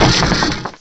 cry_not_golett.aif